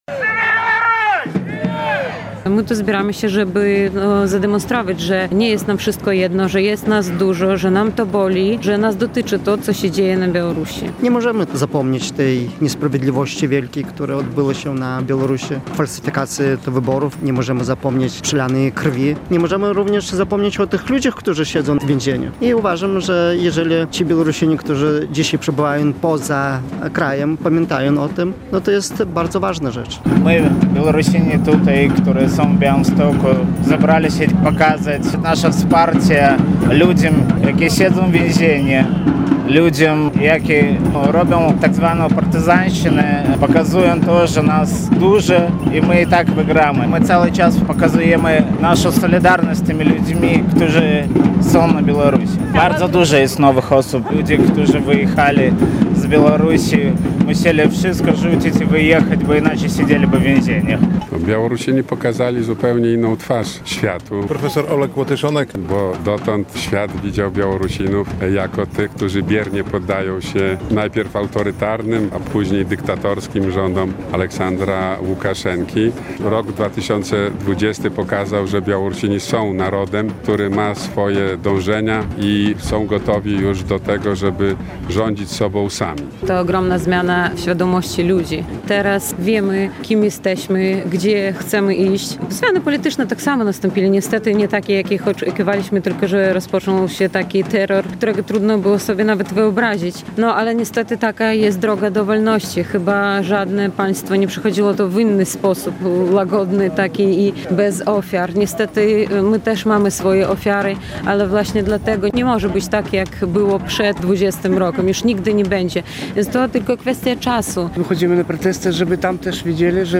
W Białymstoku w niedzielę (08.08) odbył się marsz w rocznicę sfałszowanych wyborów na Białorusi. Skandowano między innymi "Białoruś żyje", "Zwyciężymy" i "Łukaszenka musi odejść".